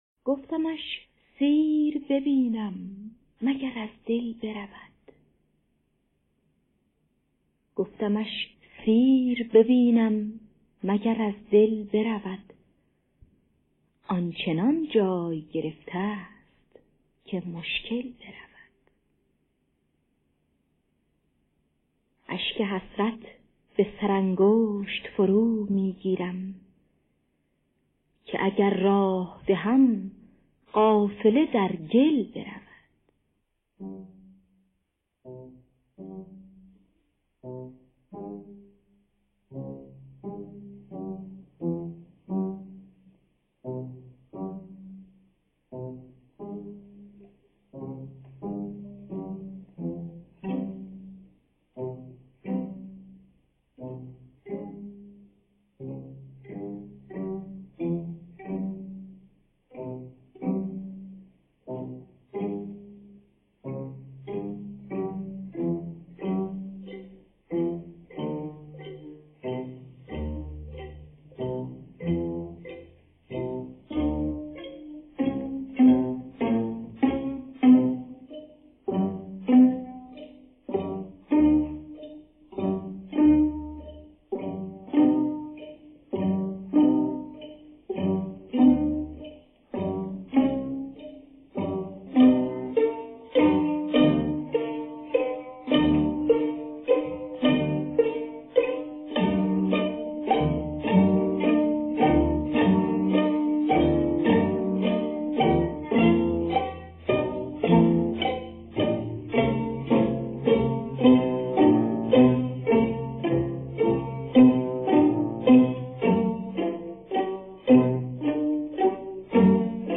دانلود گلهای رنگارنگ ۴۵۱ با صدای الهه در دستگاه دشتی.